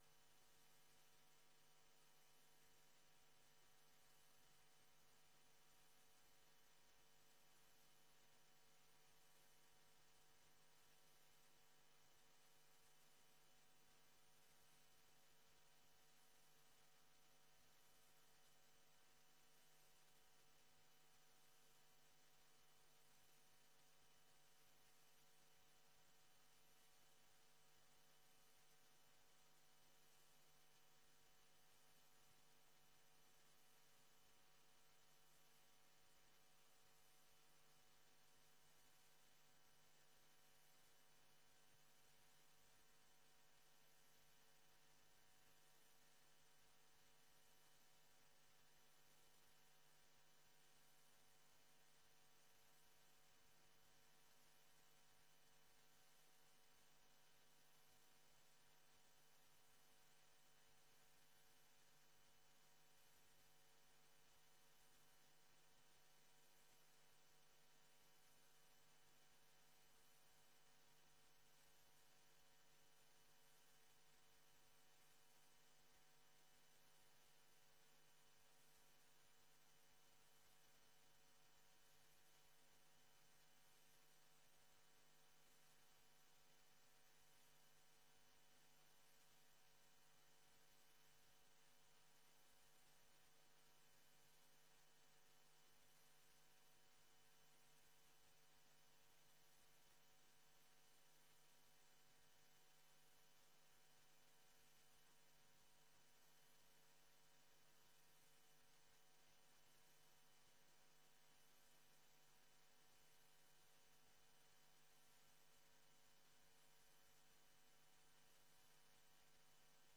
Locatie: De Lockhorst, Sliedrecht
Deze vergadering wordt live uitgezonden
Iedereen is in de gelegenheid vragen te stellen tijdens deze beeldvormende vergadering.